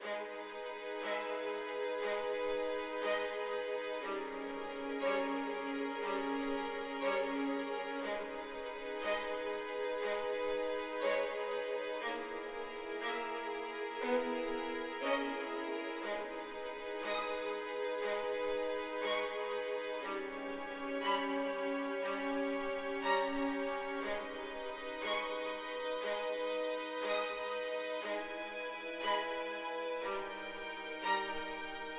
小提琴管弦乐3
描述：小提琴管弦乐3 120 BPM
Tag: 120 bpm Classical Loops Violin Loops 5.38 MB wav Key : Unknown